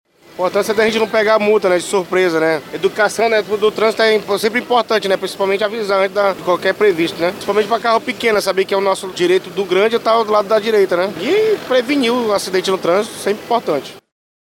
O caminhoneiro